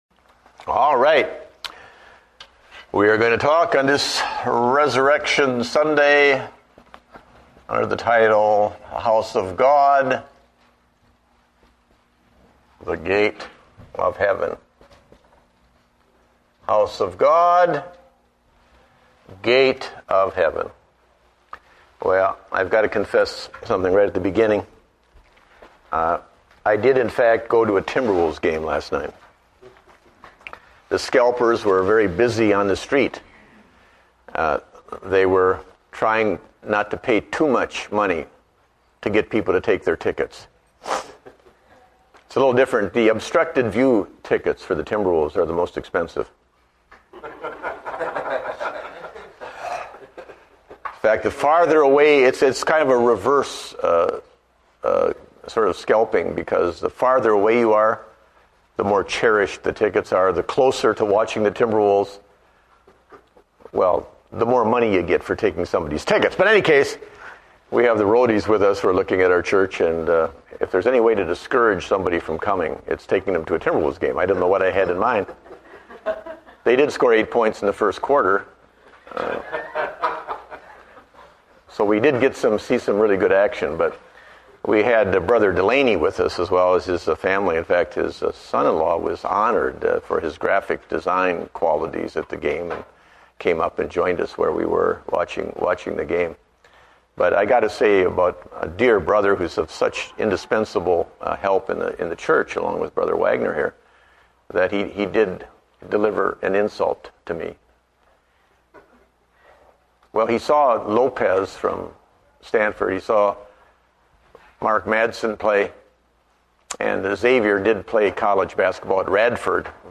Date: April 12, 2009 (Adult Sunday School)